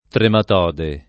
trematode [ tremat 0 de ]